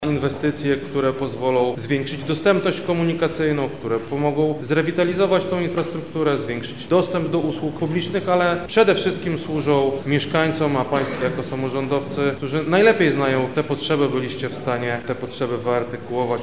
O szczegółach inicjatywy mówi wojewoda lubelski Krzysztof Komorski: